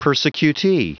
Prononciation du mot persecutee en anglais (fichier audio)
Prononciation du mot : persecutee